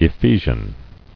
[E·phe·sian]